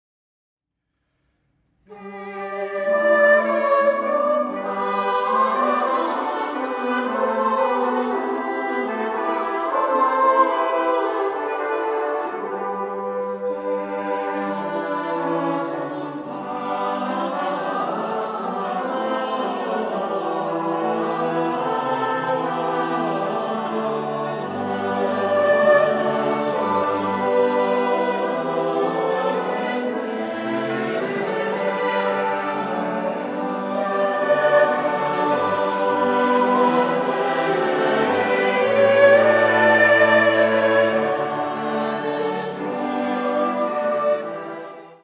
twelve motets
five-voice